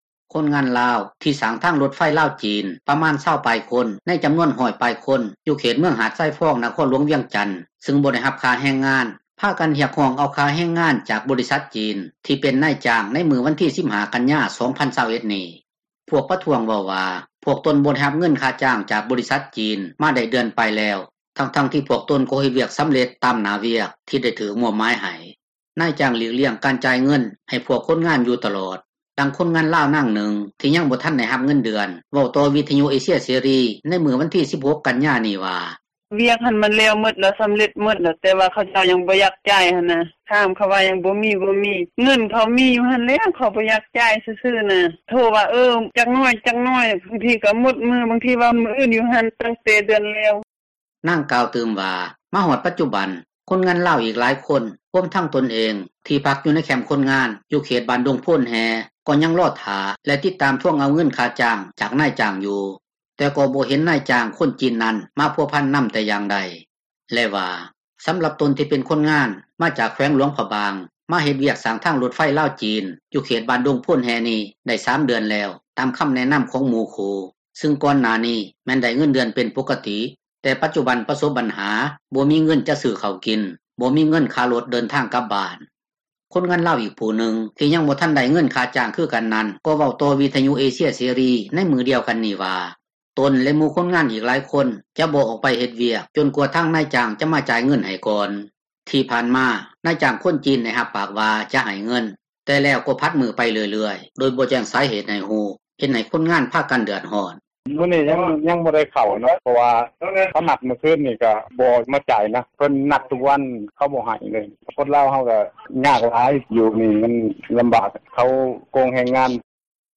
ນາຍຈ້າງຫຼີກລ້ຽງການຈ່າຍເງິນ ໃຫ້ພວກຄົນງານຢູ່ຕລອດ, ດັ່ງຄົນງານລາວນາງນຶ່ງ ທີ່ຍັງ ບໍ່ທັນໄດ້ຮັບເງິນເດືອນ ເວົ້າຕໍ່ວິທຍຸເອເຊັຽເສຣີໃນມື້ວັນທີ 16 ກັນຍານີ້ວ່າ: